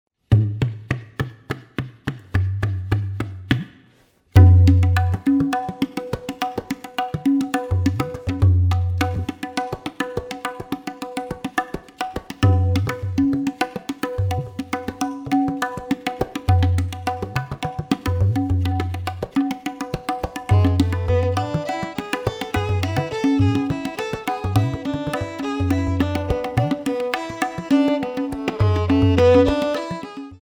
tabla, djembe
violin